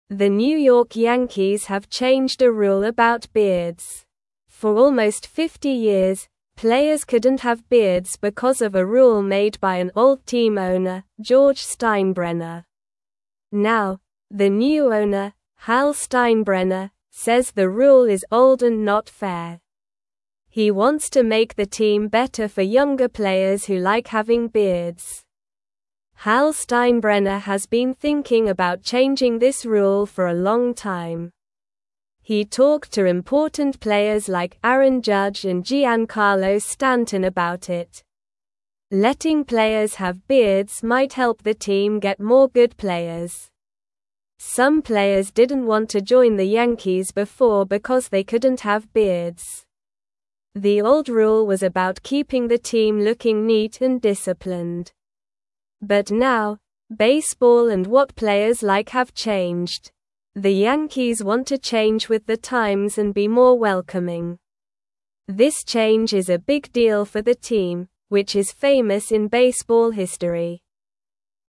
Slow
English-Newsroom-Lower-Intermediate-SLOW-Reading-Yankees-Players-Can-Now-Have-Beards-Again.mp3